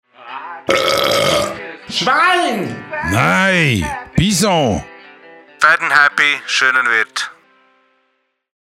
Neuer Radiospot
Februar 2014 Letzte Beiträge Unsere neue Radiowerbung hören http